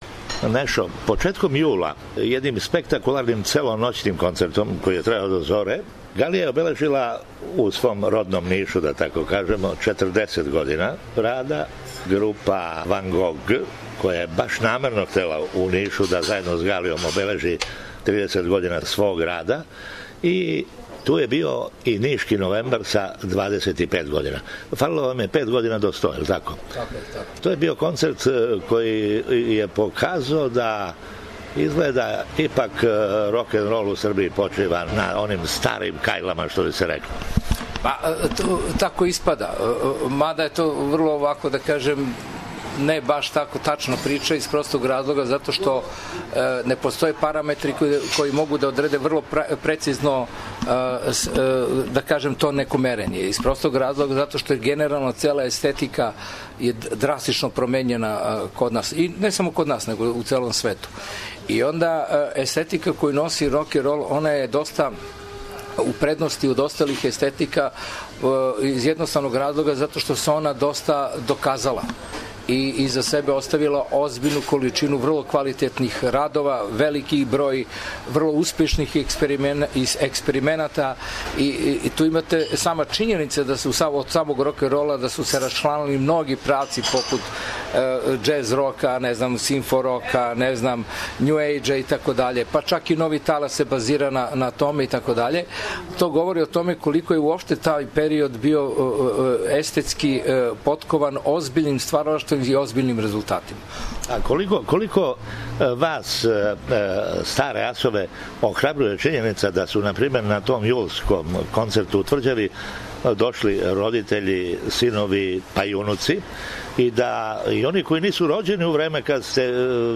у разговору са Нешом Галијом пребројавао је године и албуме ове групе